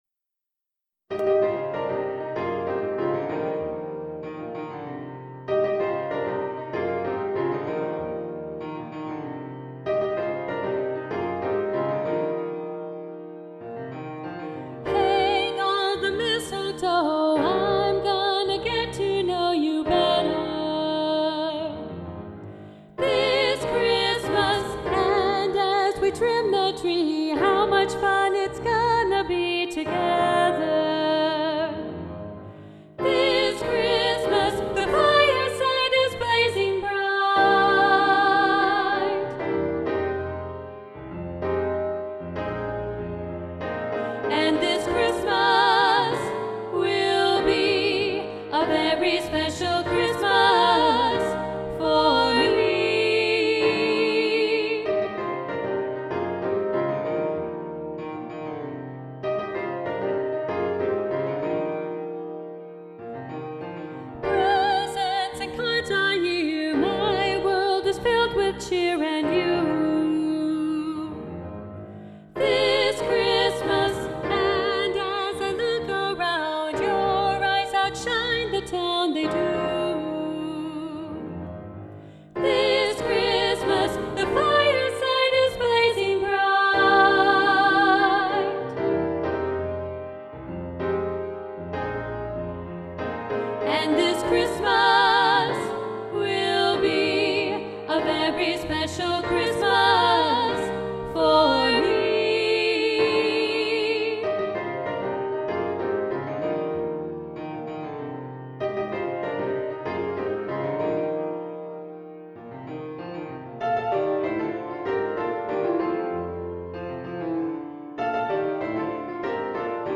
This Christmas SSA – Alto Muted – arr. Roger Emerson